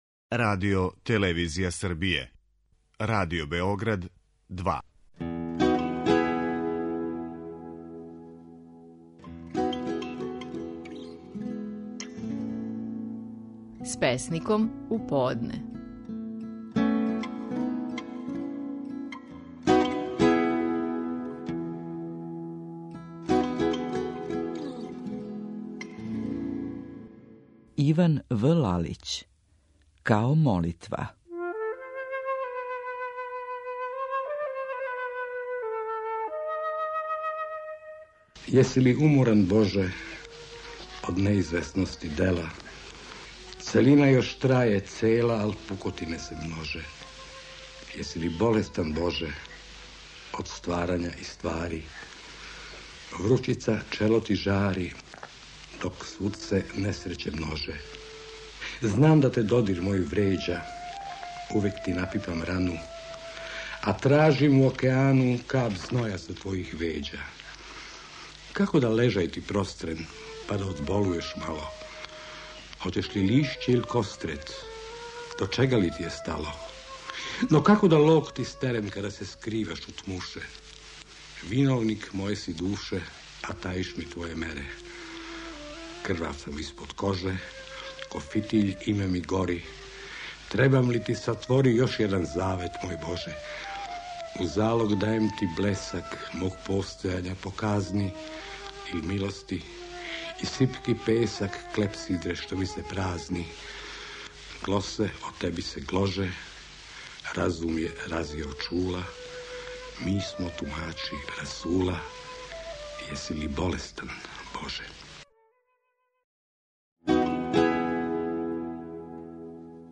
Стихови наших најпознатијих песника, у интерпретацији аутора.
Иван В. Лалић говори стихове песме „Као молитва".